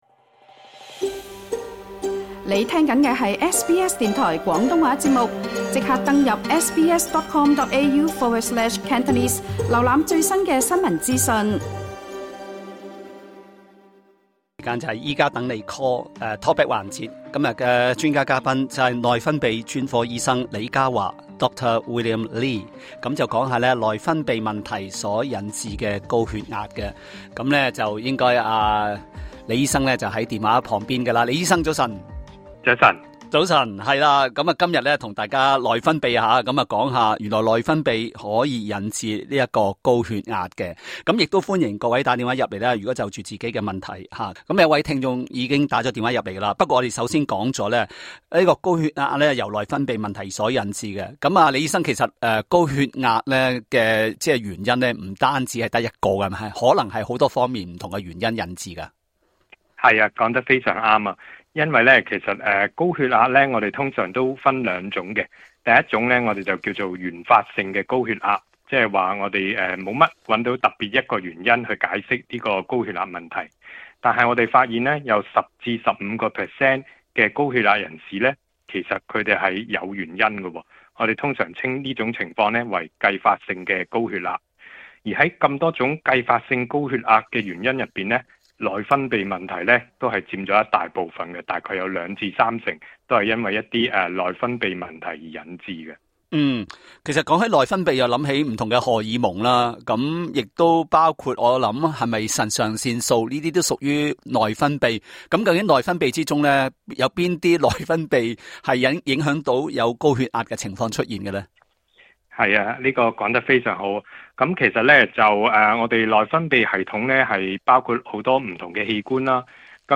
在本集《醫家等你Call》talkback 環節